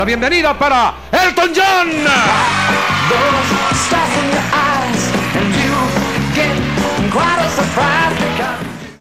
Respostes d'Elton John a les preguntes, en una roda de premsa feta a Madrid, amb traducció al castellà.